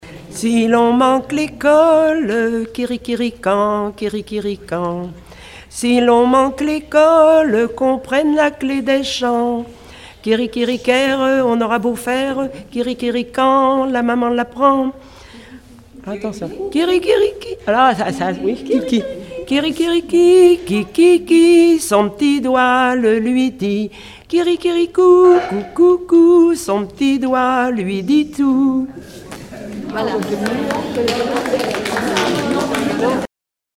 enfantine : lettrée d'école
Regroupement de chanteurs du canton
Pièce musicale inédite